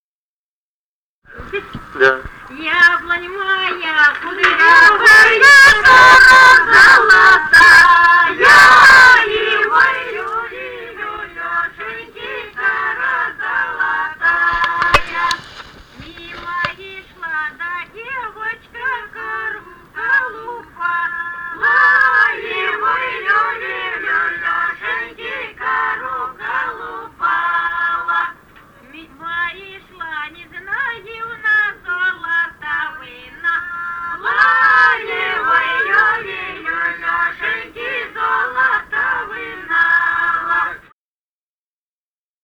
Музыкальный фольклор Климовского района 029. «Яблонь моя кудрявая» (хороводная).